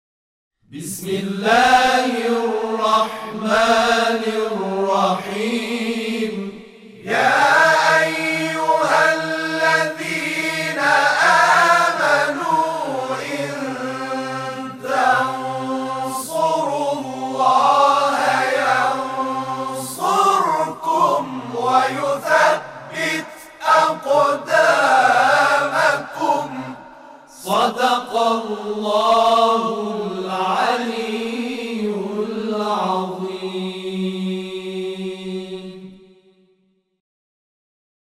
Bacaan Beramai-ramai Ayat ke-7 Surah Muhammad (SAW) oleh Kumpulan "Muhammad Rasulullah (SAW)"
Laporan IQNA; Kumpulan ini yang aktif dalam bidang madah dan tilawah beramai-ramai, dalam rangkaian program mereka, membacakan ayat suci berikut dari Surah Muhammad (SAW):
tanda nama: Tilawah Al-Qurah kumpulan Nasyid